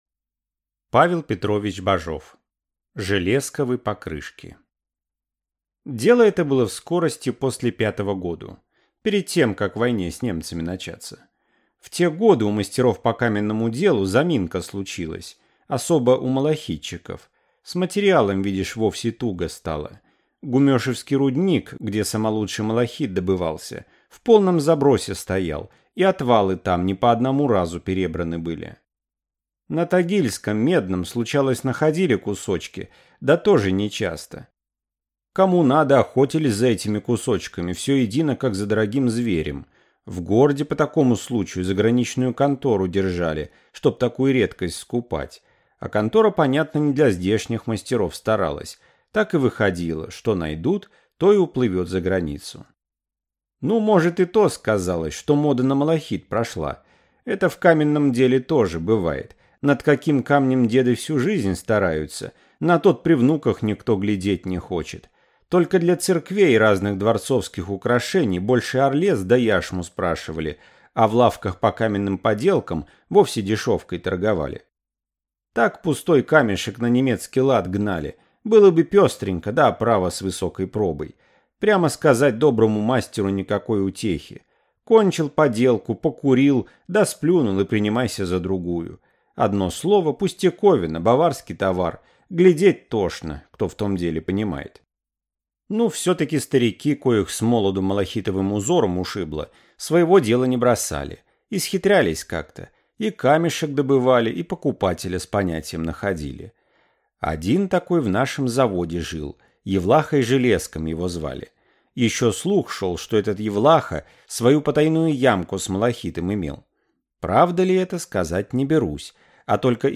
Аудиокнига Железковы покрышки | Библиотека аудиокниг